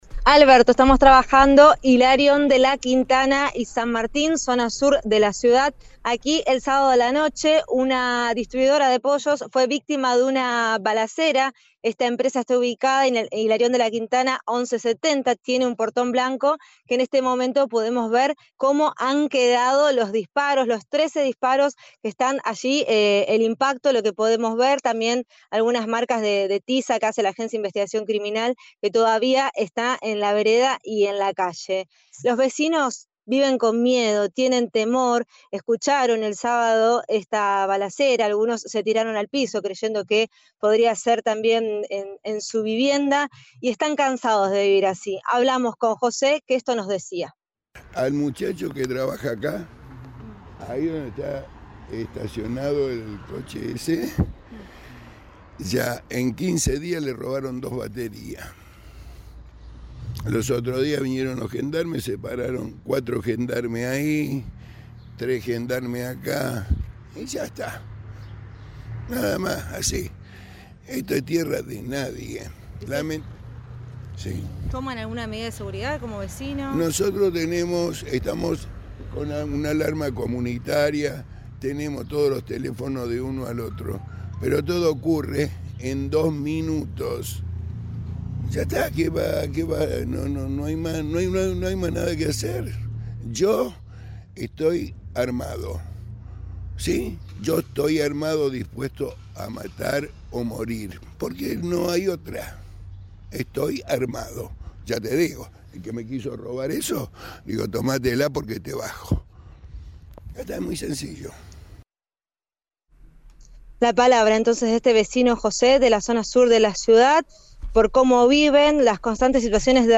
Uno de ellos dialogó con el móvil en Siempre Juntos de Cadena 3 Rosario y dijo: “Esto es tierra de nadie” y aseguró que están “hartos de vivir así”.